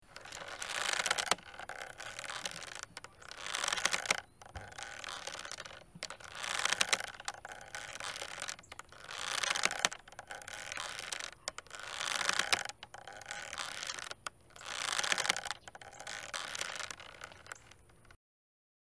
Звуки рвущейся веревки или каната, натягивание и связывания для монтажа видео в mp3
2. Звук покачивания на веревке ( повешенный висит и на ветру качается из вестерна)